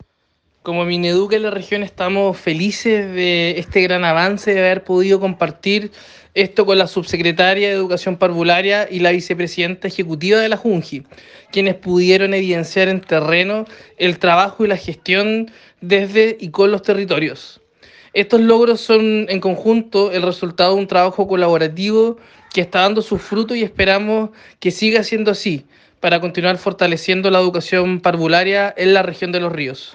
Seremi-Educacio-por-visita-subse-parvularia.mp3